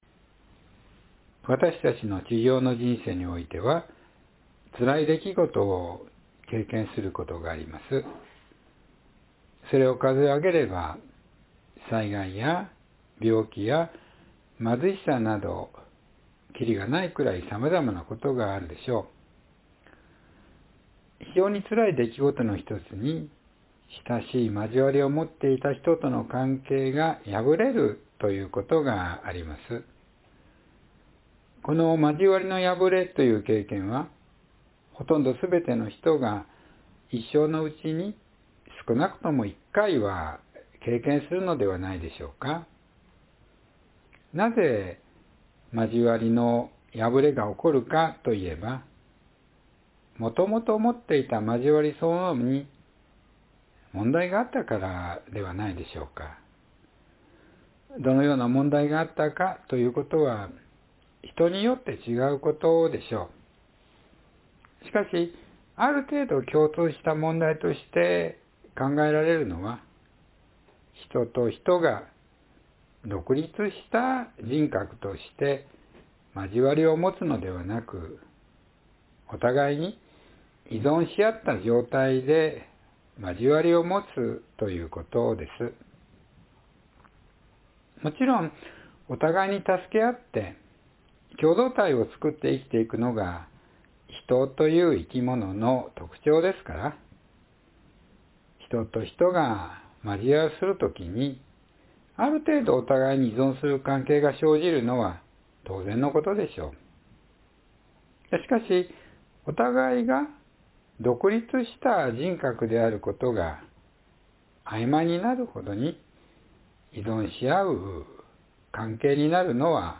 （3月22日の説教より）